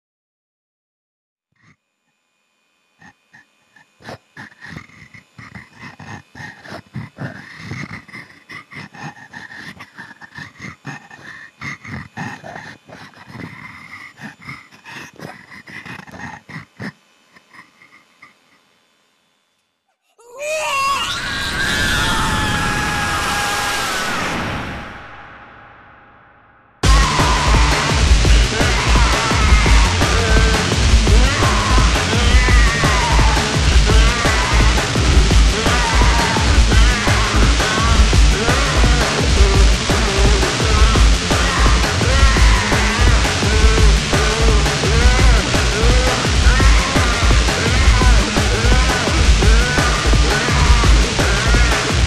bass, guitare.
vocals
vielle